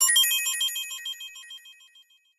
snd_sparkle_glock.wav